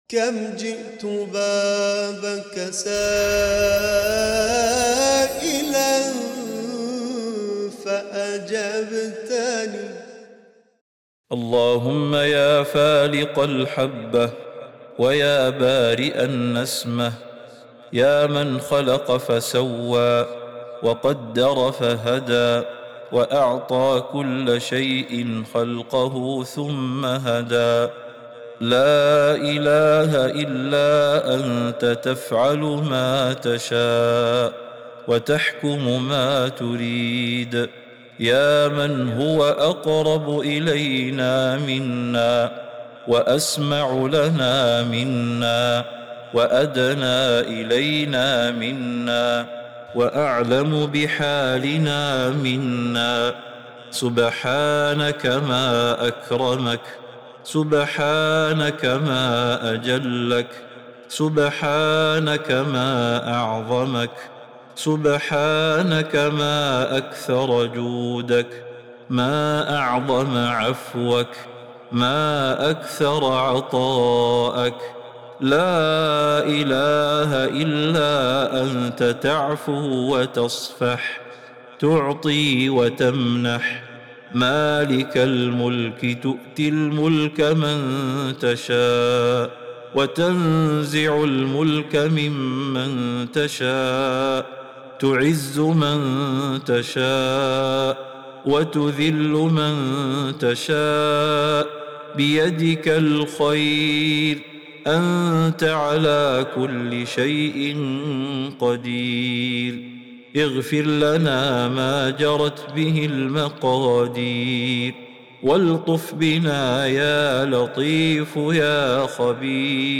دعاء خاشع ومناجاة مؤثرة تفيض بالتسبيح والثناء على الله تعالى، والتعرف على أسمائه وصفاته من الخالق الرازق الودود. يتضمن الدعاء طلب التوبة والرحمة والثبات على الذكر والشكر، والرغبة في الفوز بالجنة.